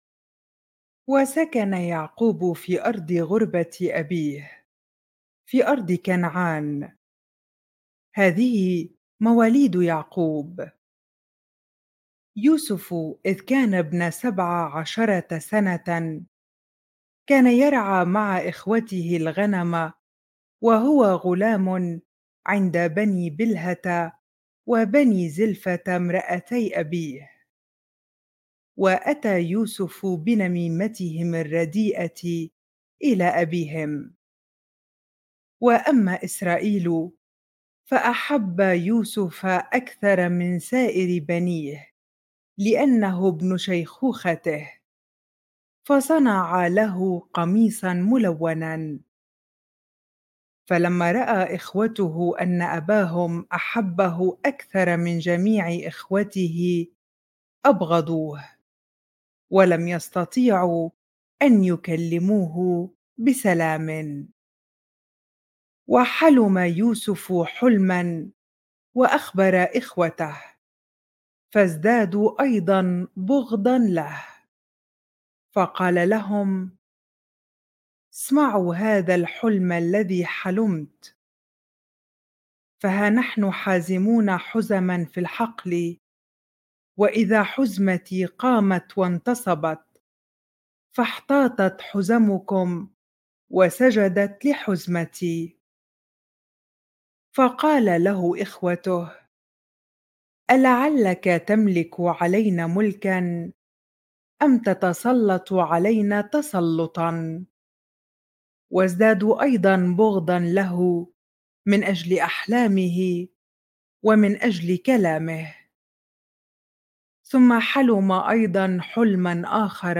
bible-reading-genesis 37 ar